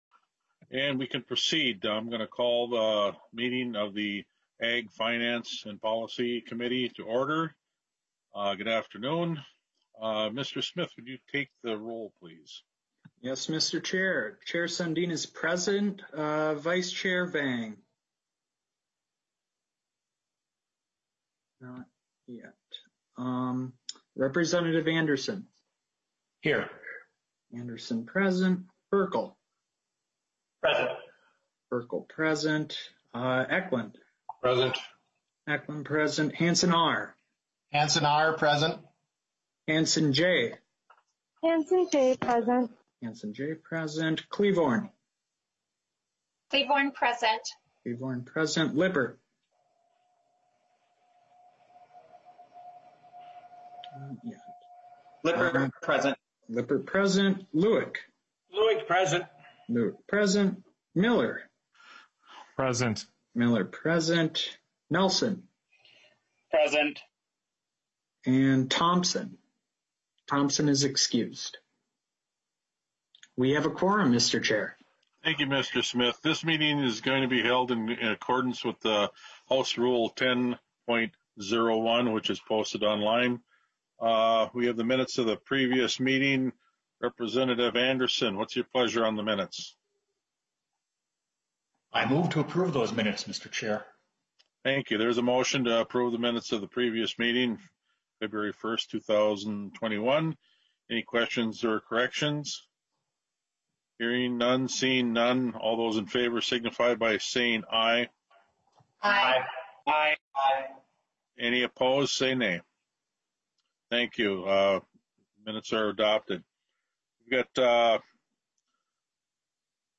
Public testimony will be taken at the end of the hearing if time is available.